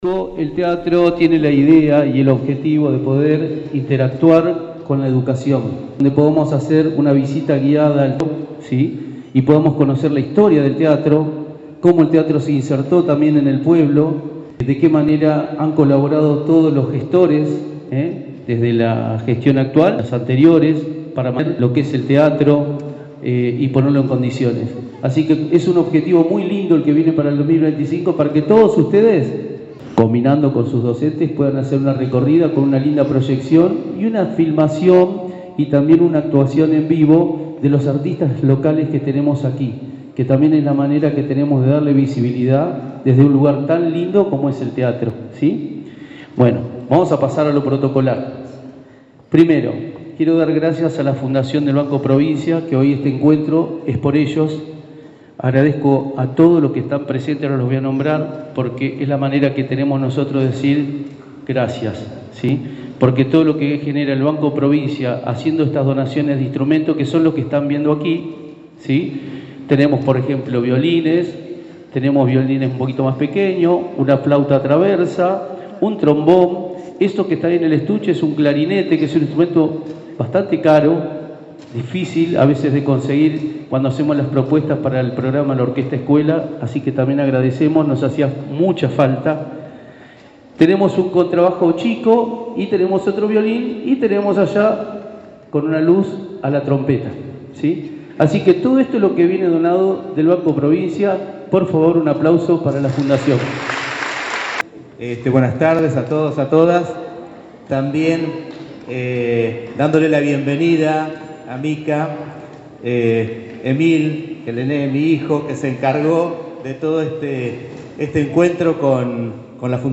La presentación tuvo lugar en el Teatro Español en el marco de una Clase Abierta de la agrupación infanto-juvenil florense y el lanzamiento de las inscripciones para el ciclo lectivo 2025.